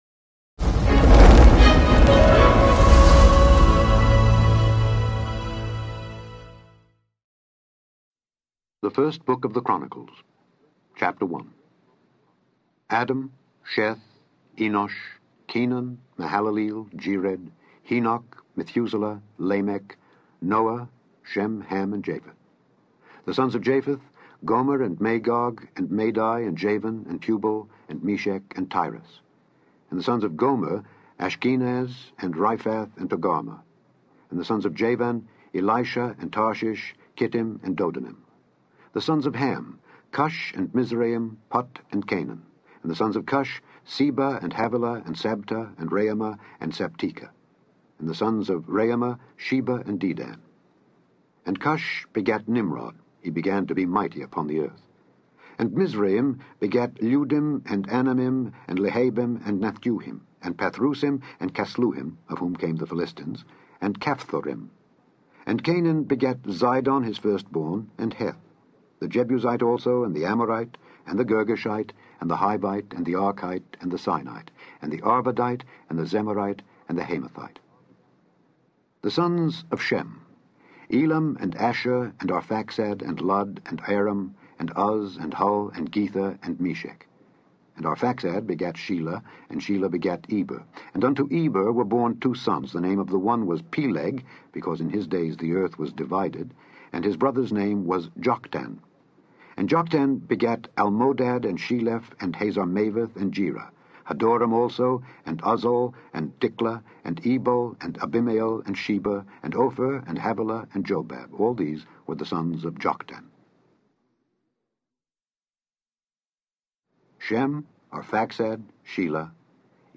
In this podcast, you can listen to Alexander Scourby read 1 Chronicles 1-4.